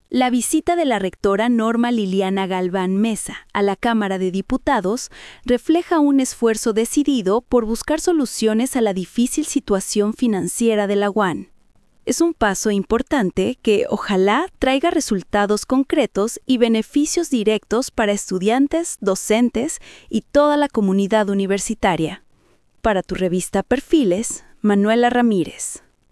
COMENTARIO EDITORIAL